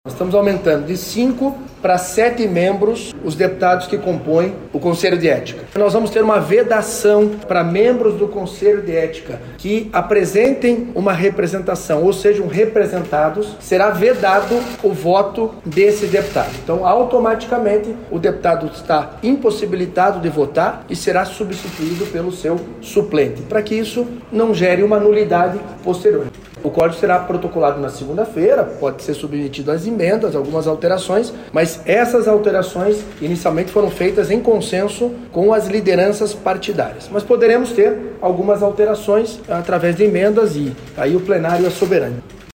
O presidente da Casa, deputado Alexandre Curi (PSD), falou sobre algumas mudanças que devem ser adotadas com o novo regulamento.